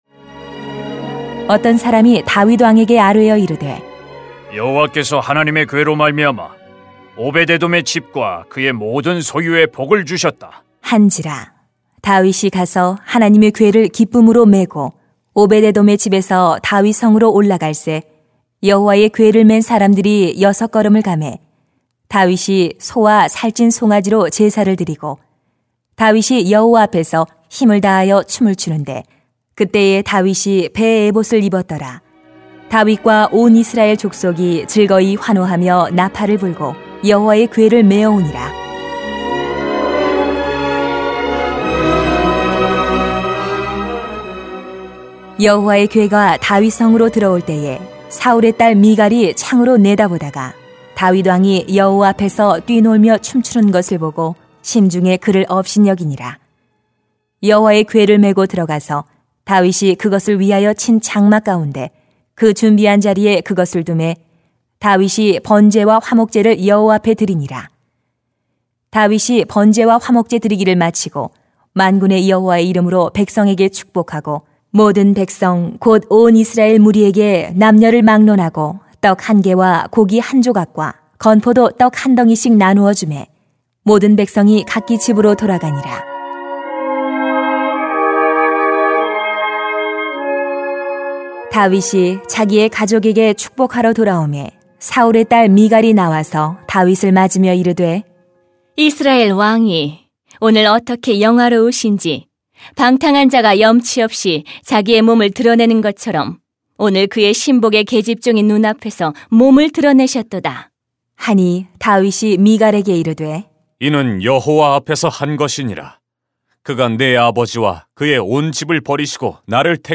[삼하 6:12-23] 예배의 즐거움을 빼앗기지 맙시다 > 새벽기도회 | 전주제자교회